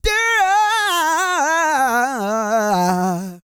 E-GOSPEL 137.wav